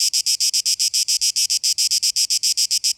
sounds_cicada_07.ogg